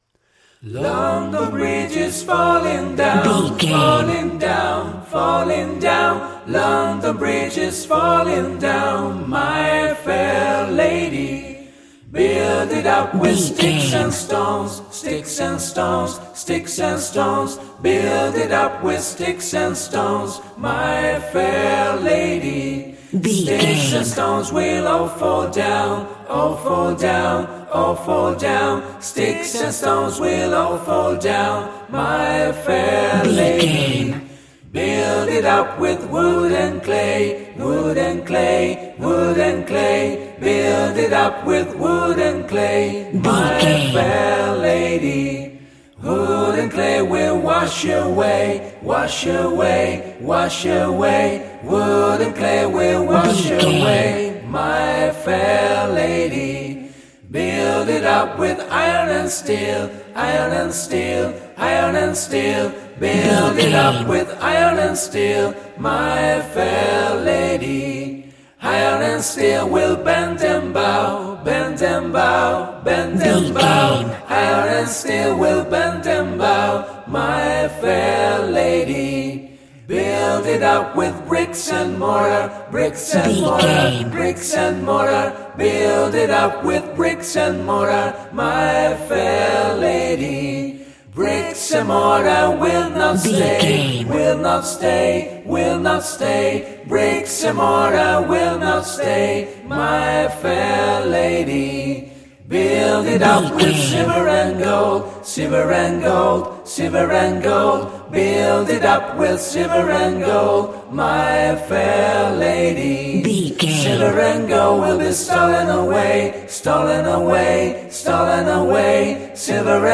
Nursery Rhyme Acapella
royalty free music
Ionian/Major
D♭
childlike
happy